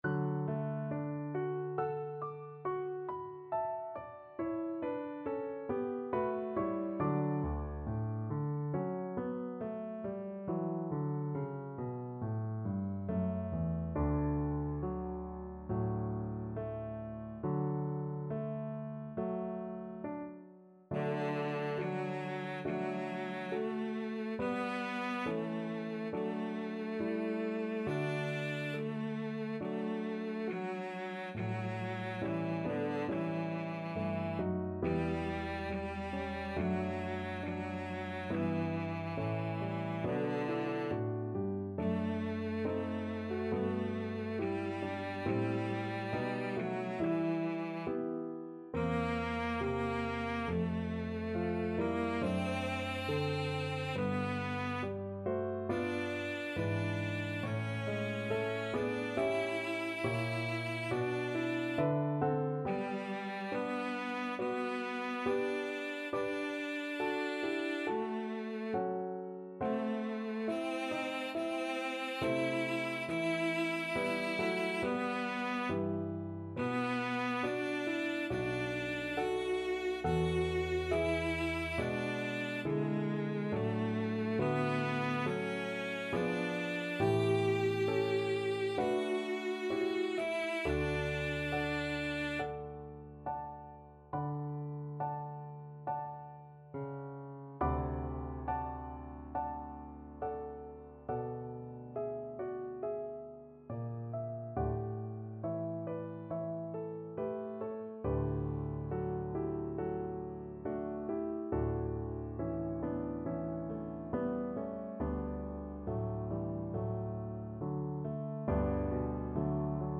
Cello
D major (Sounding Pitch) (View more D major Music for Cello )
Slow =c.69
Classical (View more Classical Cello Music)